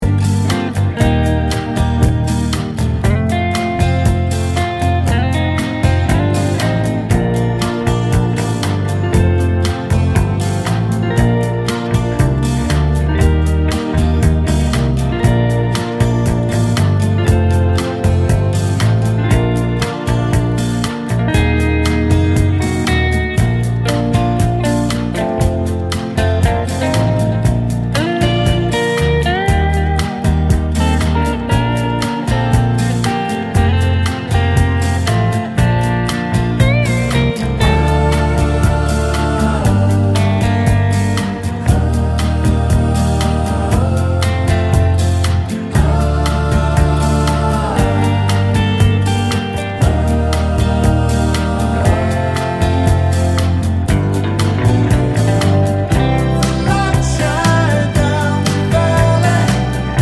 Two Semitones Down Pop (1970s) 2:35 Buy £1.50